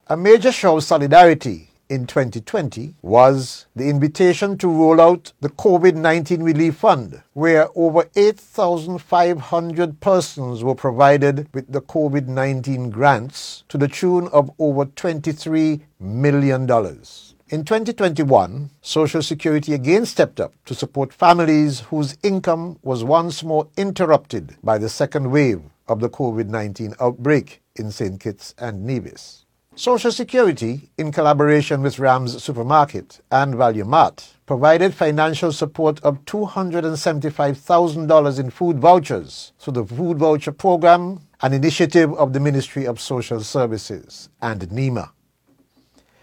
In an address on Tuesday, Minister responsible for Social Security, Eugene Hamilton, mentioned a few examples of how the Board has assisted persons: